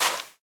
sand1.ogg